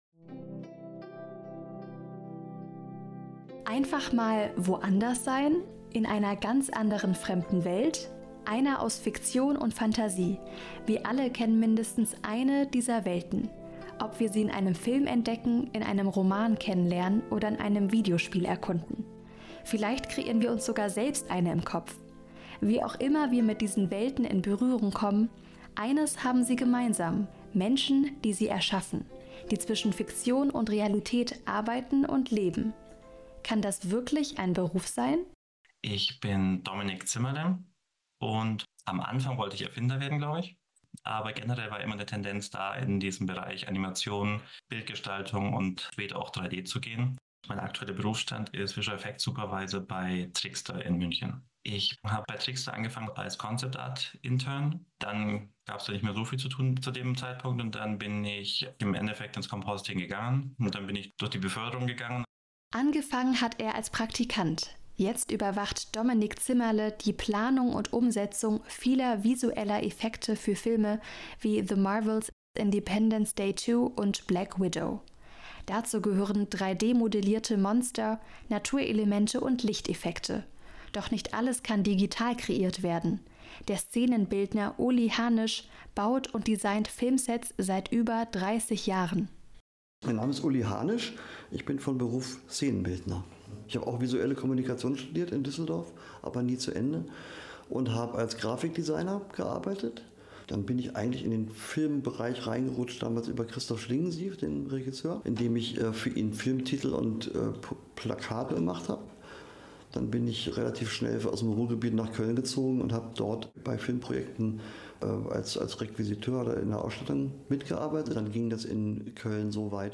Creating-Worlds.-Audio-Feature.mp3